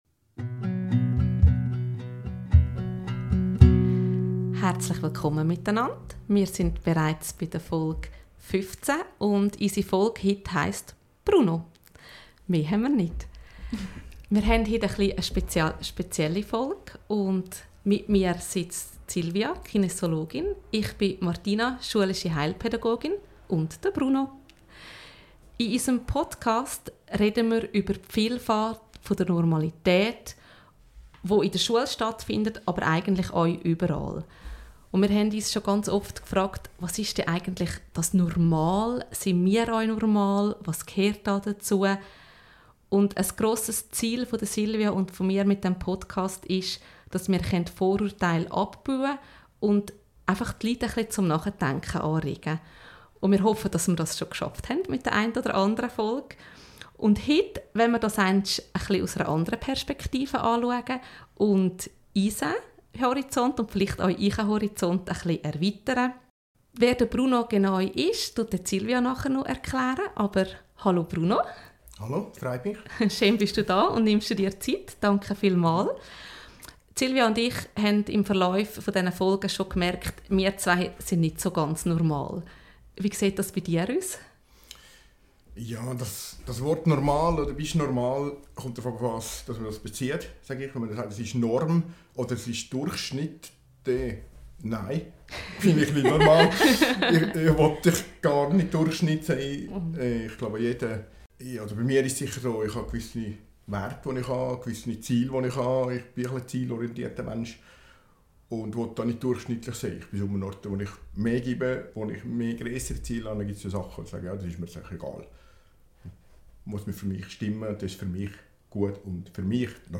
Wir sprechen über die Herausforderungen und Chancen in der Zusammenarbeit mit Jugendlichen. Es ist eine abwechslungsreiche, interessante Folge entstanden, in der wir auch wieder viel zu Lachen hatten.